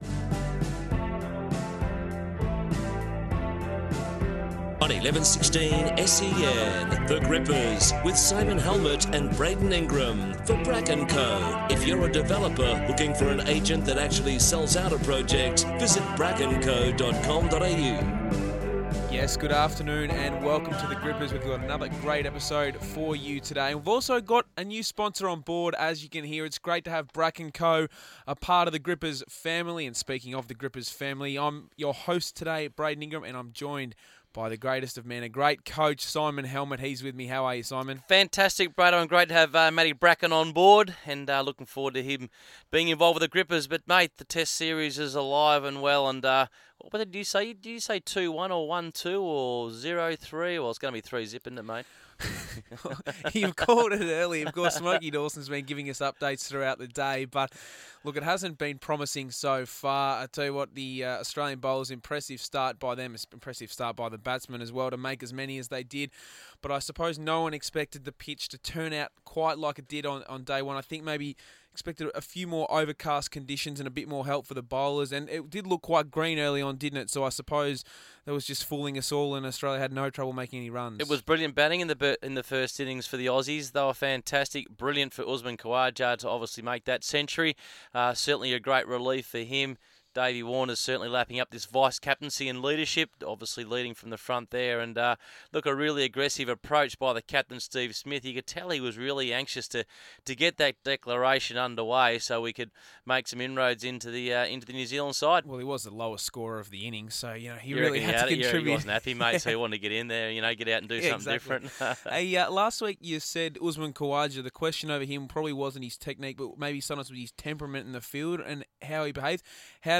chat all things cricket